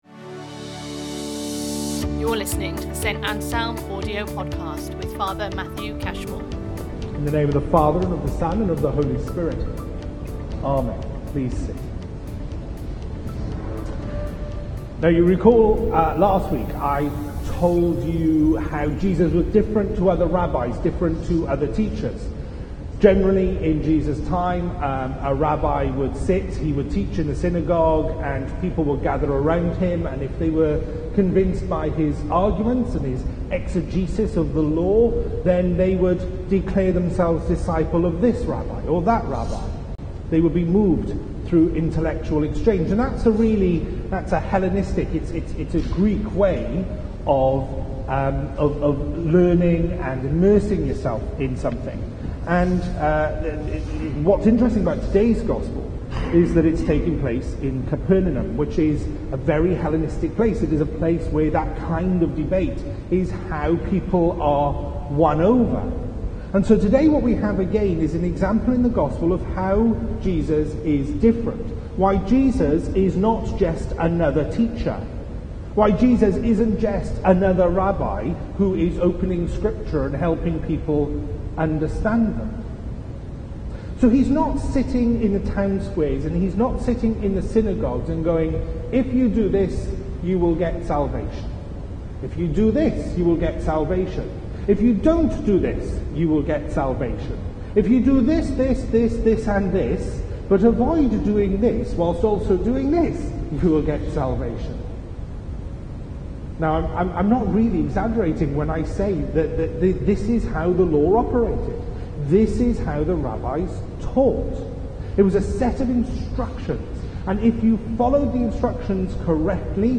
The Authority of Christ Date January 31, 2021 Topic The Gospels , The Holy Spirit Series Sunday Sermons Book Mark Watch Listen Read Save Mark 1:21-28 That authority is awesome, and if we accept it in its fullness, it is a game changer in our lives.